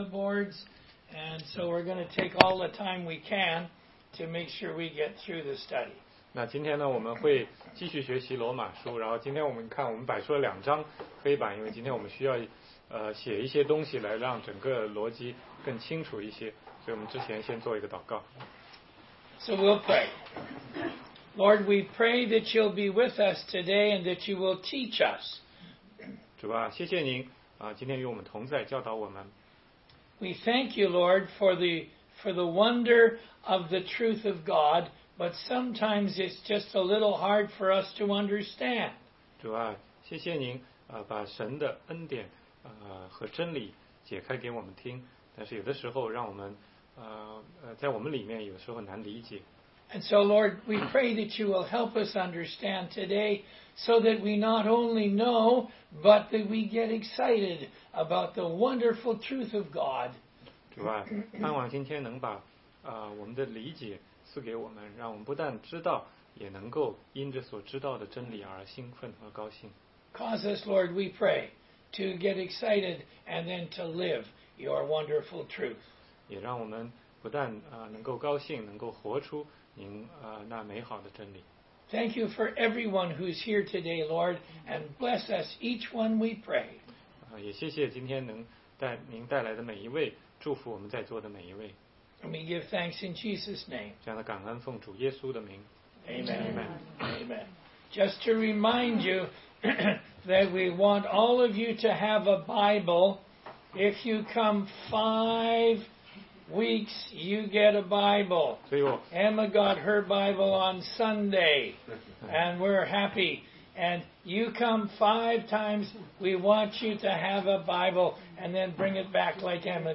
16街讲道录音 - 罗马书5章12节-6章7节